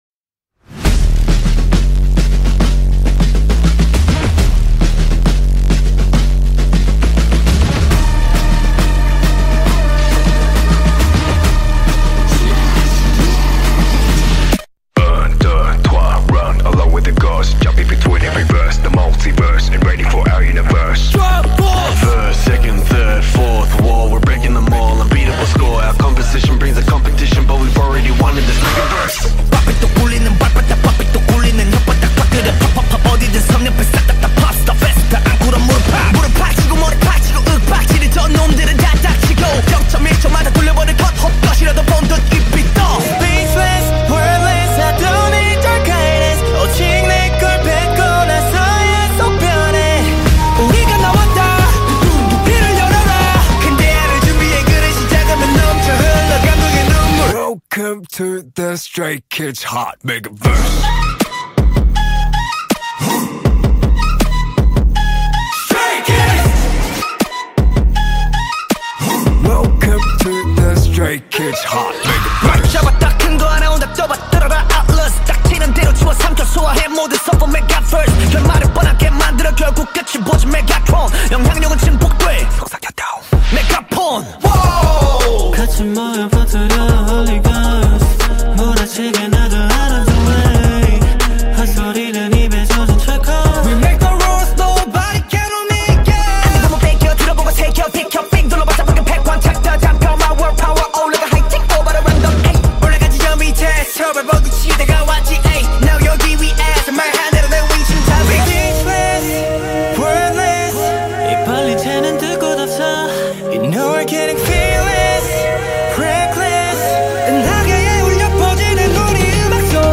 BPM60-240
Audio QualityPerfect (High Quality)